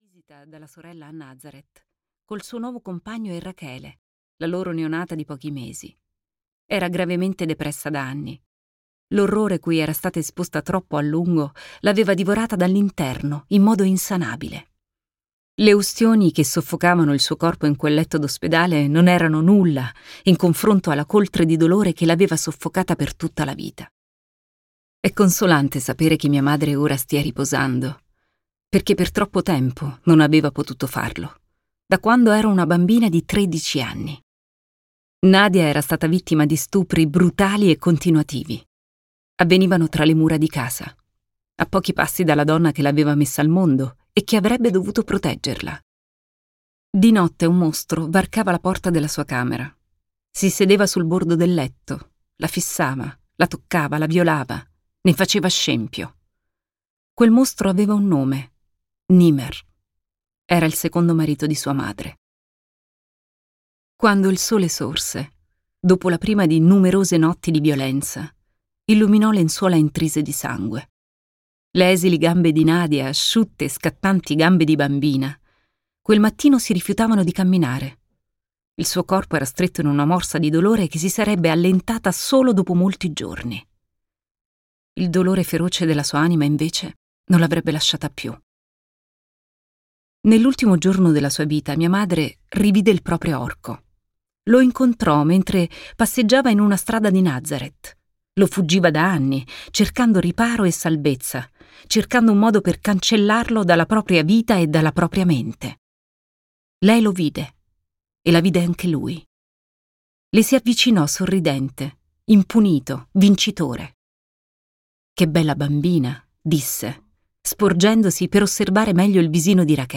"Il cambiamento che meritiamo" di Rula Jebreal - Audiolibro digitale - AUDIOLIBRI LIQUIDI - Il Libraio
• Letto da: Teresa Saponangelo